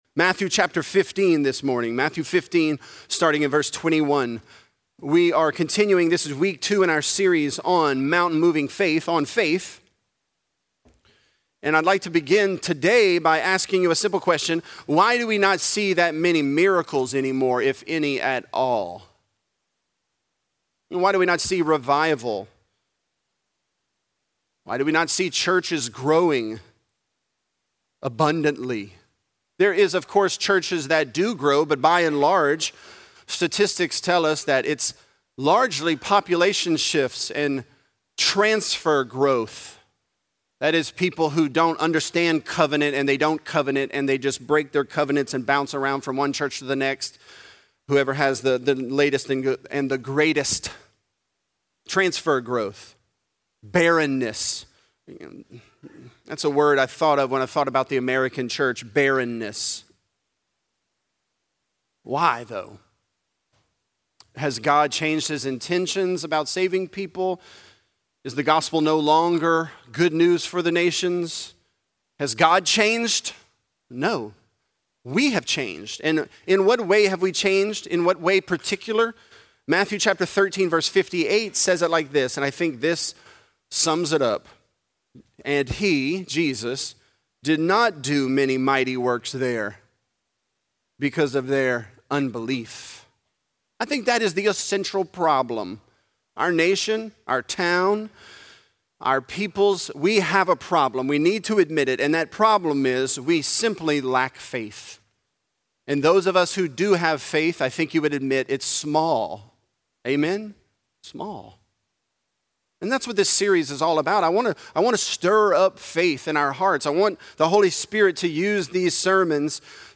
Mountain Moving Faith: The Canaanite Dog | Lafayette - Sermon (Matthew 15)